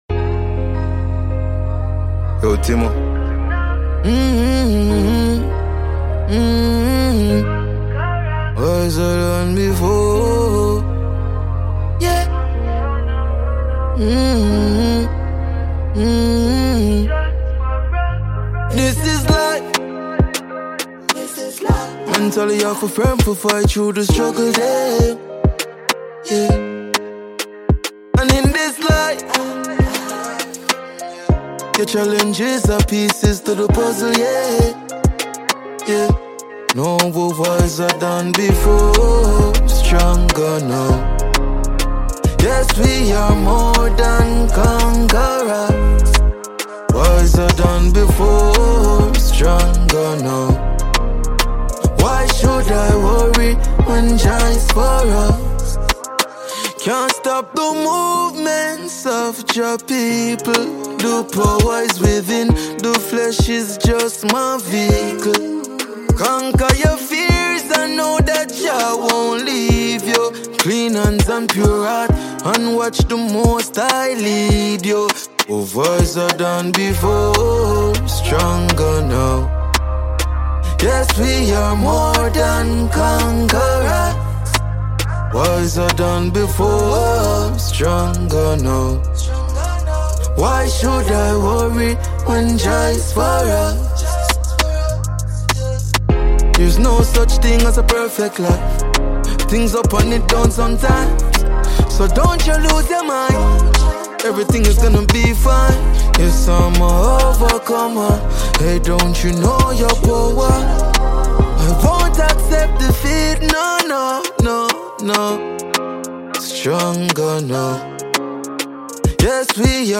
Jamaican dancehall